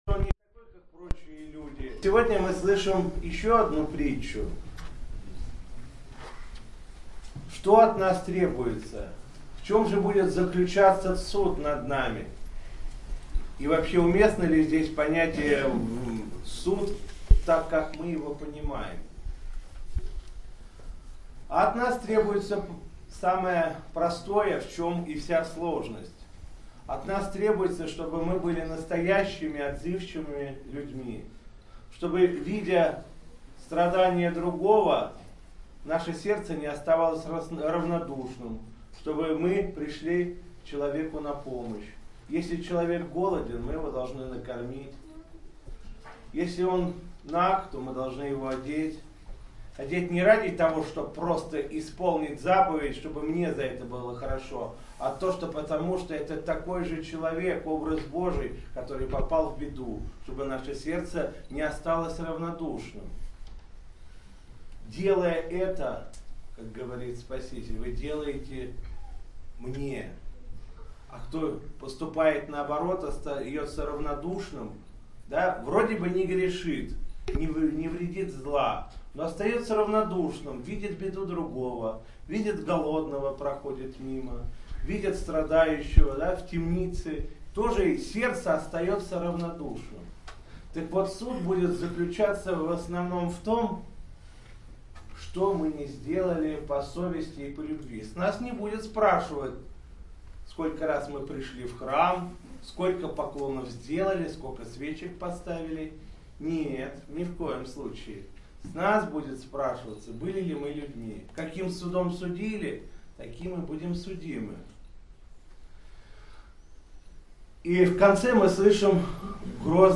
6 марта 2016 года, в Неделю мясопустную, о Страшном Суде, митрополит Вологодский и Кирилловский Игнатий в Архиерейском подворье храма Николая Чудотворца на Глинках перед иконой целителя Пантелеимона возглавил Божественную литургию.
После чтения Евангелия митрополит Игнатий обратился к присутствующим с архипастырским словом на тему Страшного Суда.